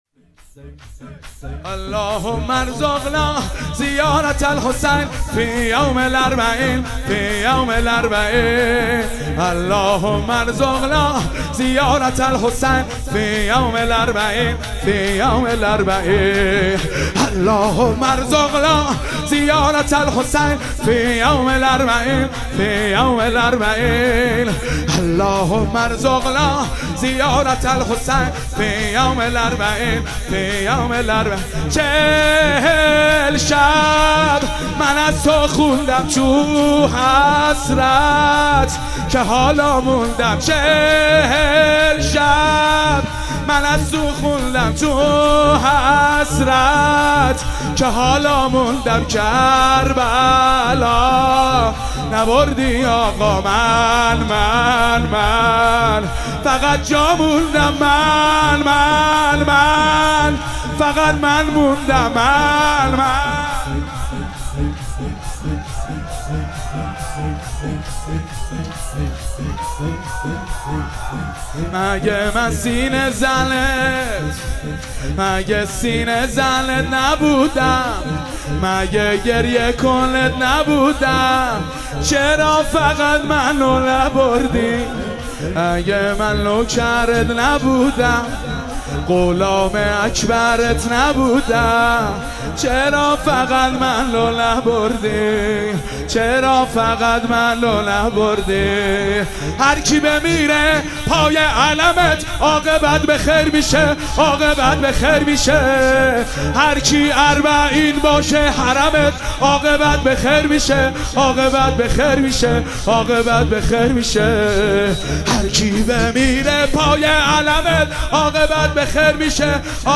اربعین - شور - اللهم الرزقنا زیارت الحسین